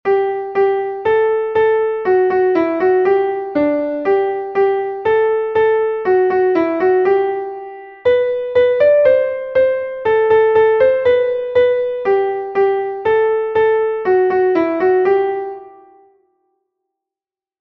Volkslied